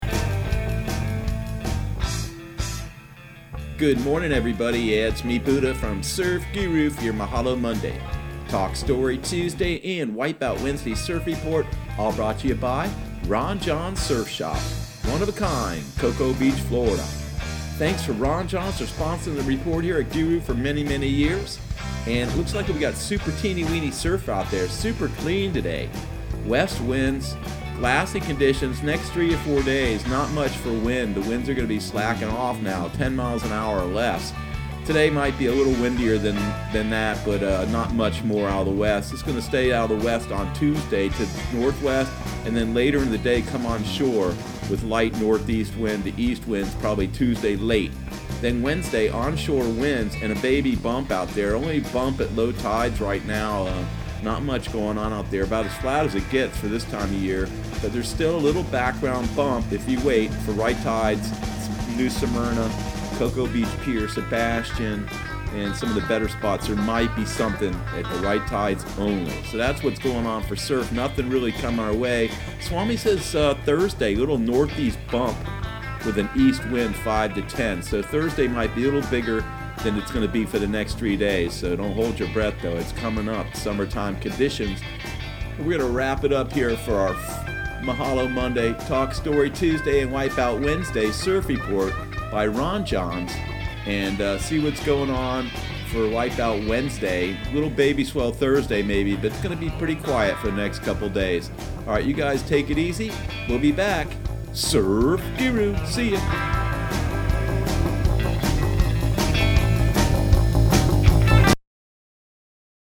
Surf Guru Surf Report and Forecast 05/13/2019 Audio surf report and surf forecast on May 13 for Central Florida and the Southeast.